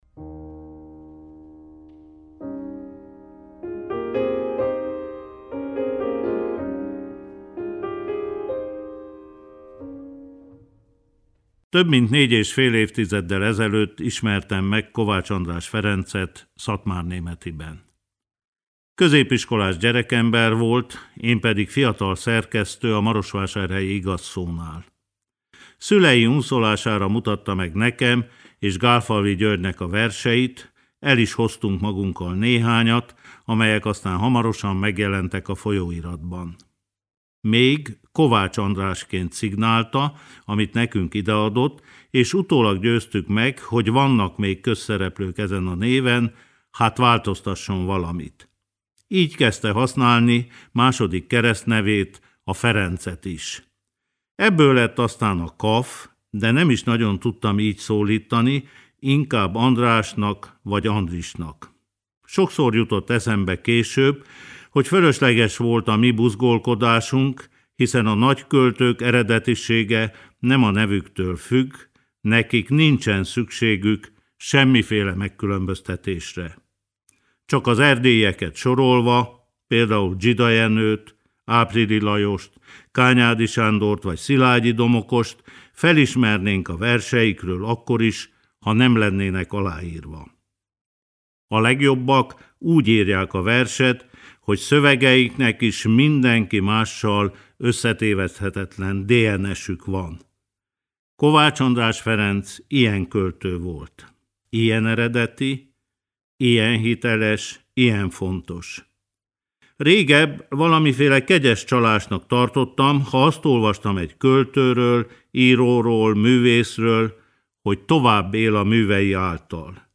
Kovács András Ferenc Kossuth-díjas költőre emlékezünk születésének 65. évfordulóján. A tavaly december 30-án elhunyt KAF végső búcsúztatóját Markó Béla költőtársa, barátja írta, és – utólagos kérésünkre – felolvasta a rádió stúdiótermében.
Zenei illusztráció: Clara Schumann Scherzo no.2, op.14 – Isata Kanneh-Mason előadásában